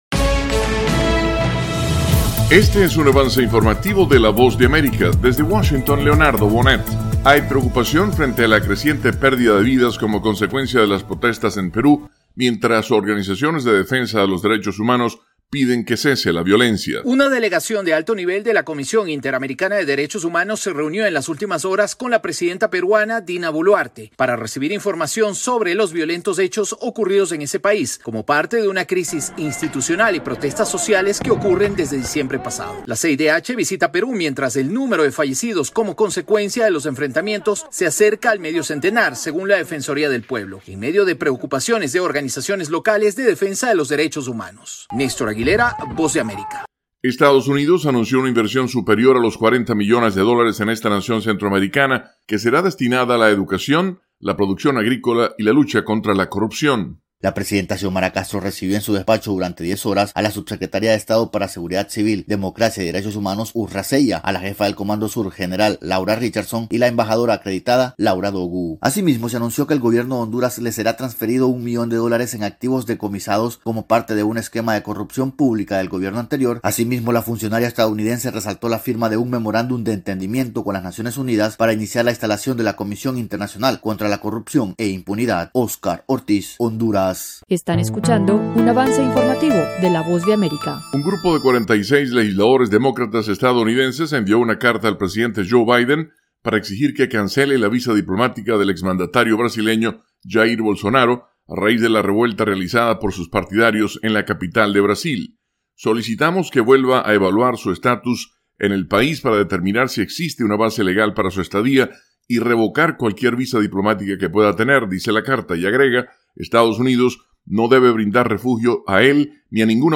El siguiente es un avance informativo presentado por la Voz de América, desde Washington,